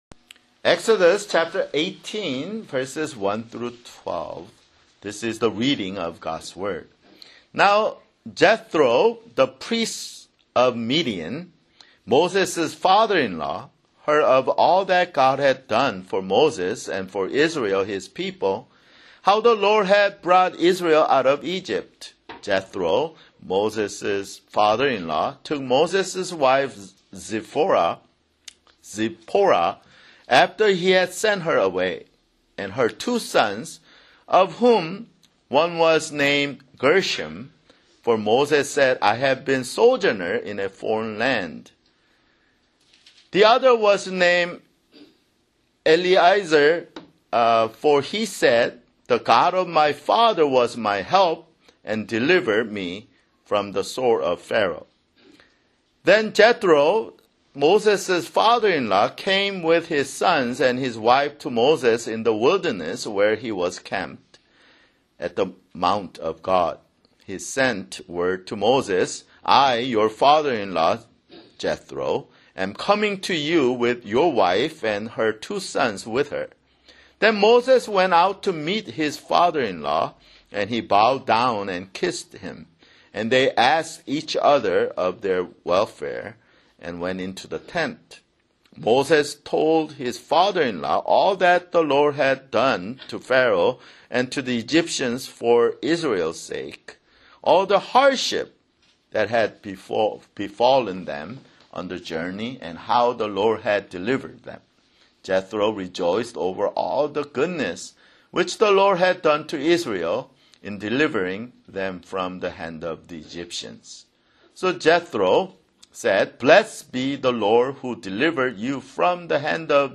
[Sermon] Exodus (42)